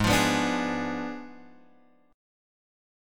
G# Diminished 7th